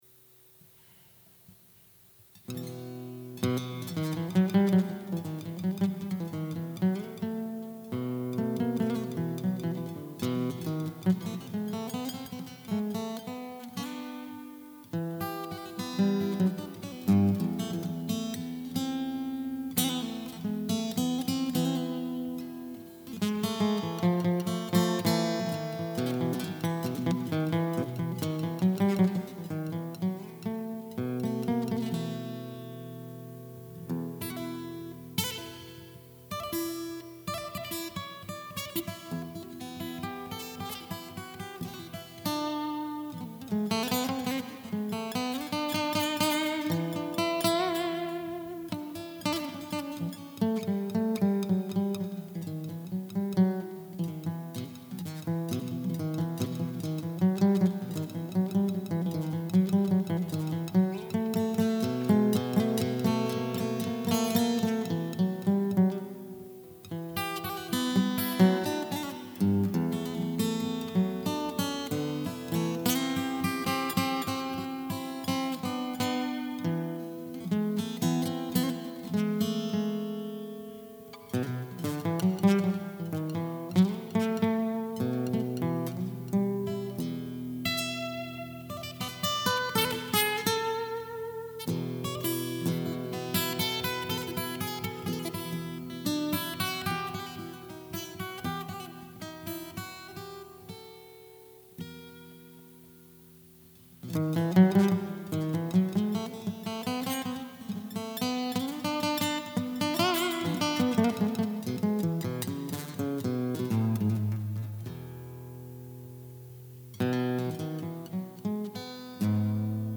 I’ve used the same arrangement (without the jazziness) as a starting point for accompanying singers, but this is a highly personalized, extemporized version that always seems to come out more North African than Indian.